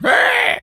pig_scream_short_03.wav